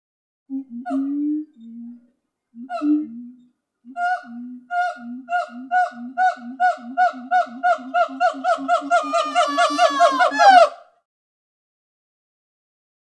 Здесь вы найдете разнообразные крики, песни и коммуникационные сигналы этих обезьян, записанные в естественной среде обитания.
два гиббона ведут беседу